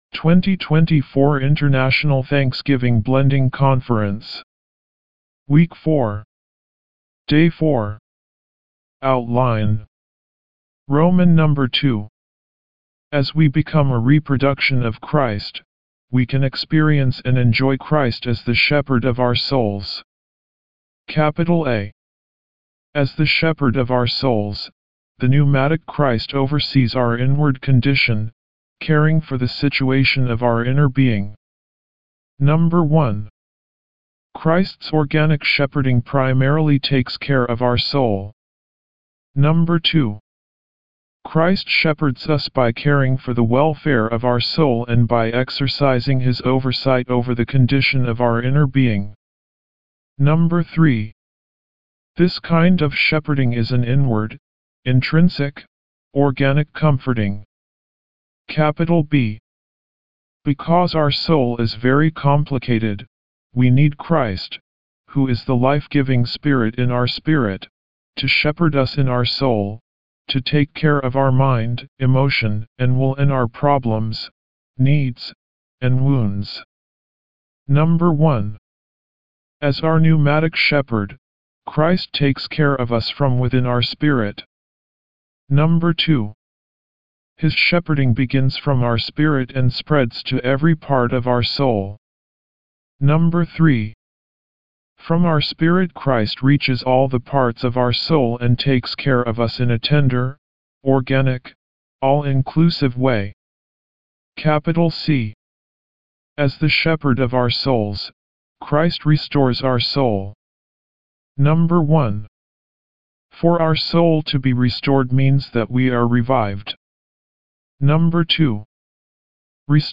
D4 English Rcite：